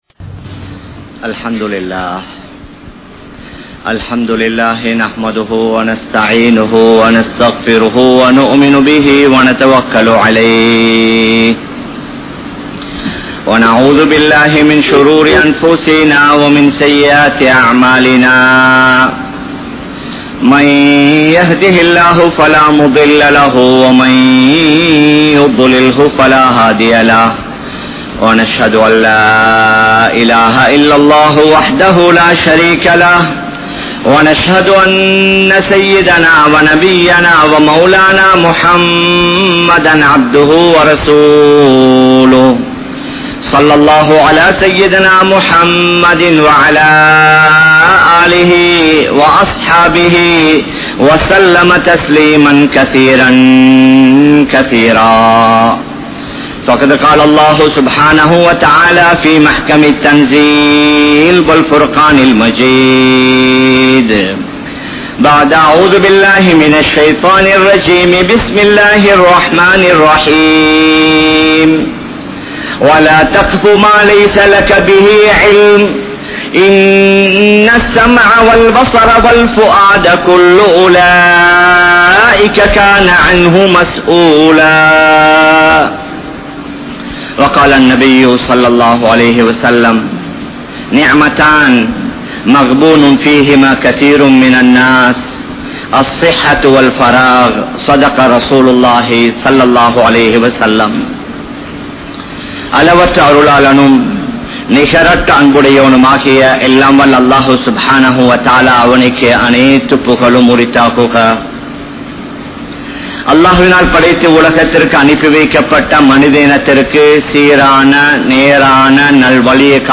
Importance of Time | Audio Bayans | All Ceylon Muslim Youth Community | Addalaichenai
Colombo 03, Kollupitty Jumua Masjith